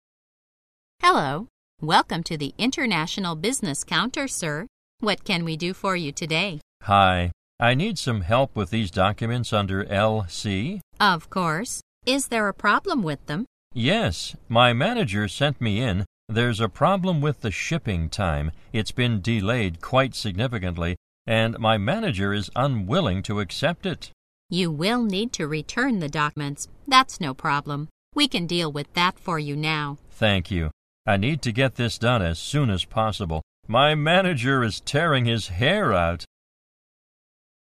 在线英语听力室银行英语情景口语 第134期:国际结算业务 拒付退单(2)的听力文件下载, 《银行英语情景口语对话》,主要内容有银行英语情景口语对话、银行英语口语、银行英语词汇等内容。